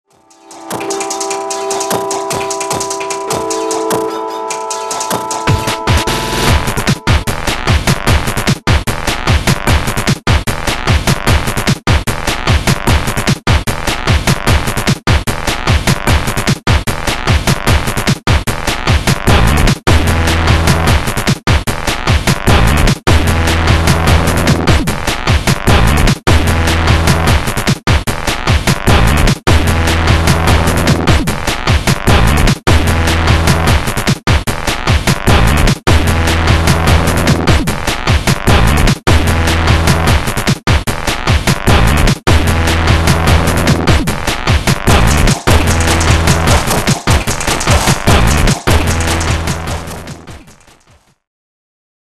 DEMO 625186 bytes / 00:52 / bpm150